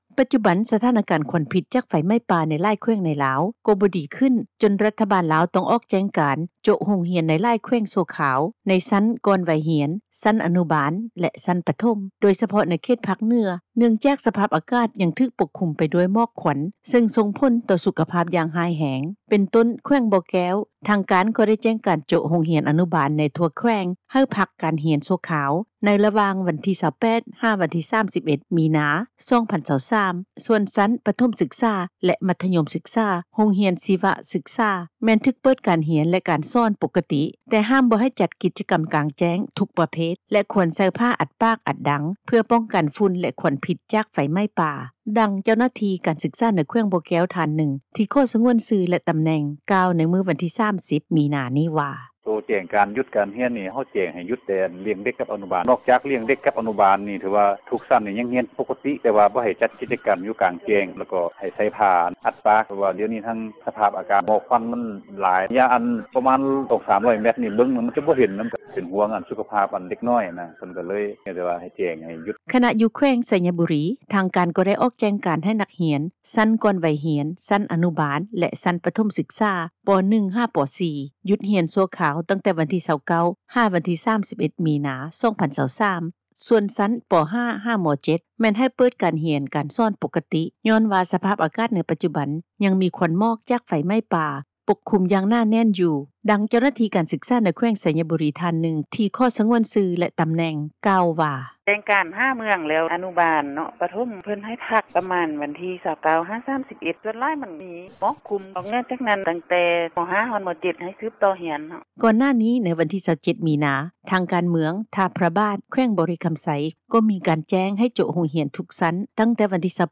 ດັ່ງ ເຈົ້າໜ້າທີ່ ການສຶກສາ ໃນແຂວງໄຊຍະບຸຣີ ທ່ານນຶ່ງ ທີ່ຂໍສງວນຊື່ ແລະ ຕໍາແໜ່ງ ກ່າວວ່າ:
ດັ່ງເຈົ້າໜ້າທີ່ ສນາມບິນສາກົລວັດໄຕ ທ່ານນຶ່ງ ທີ່ສງວນຊື່ ແລະ ຕໍາແໜ່ງກ່າວວ່າ: